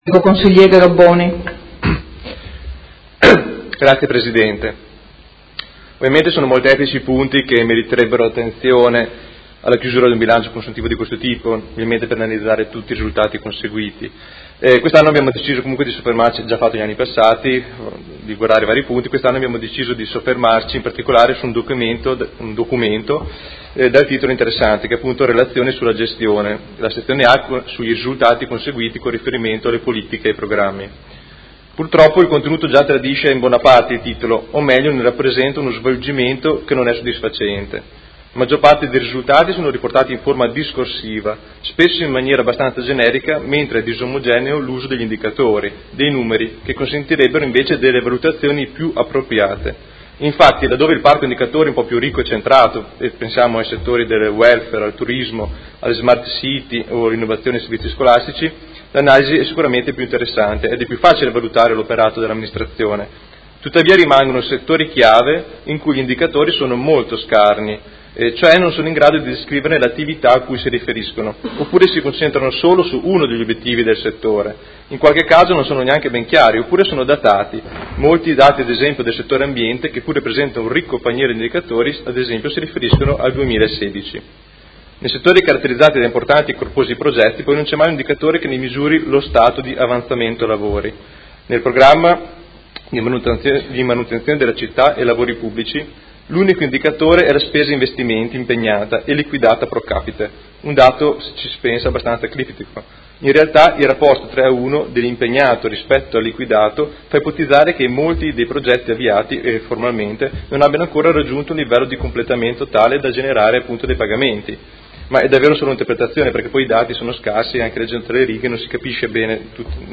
Seduta del 26/04/2018 Dichiarazione di voto.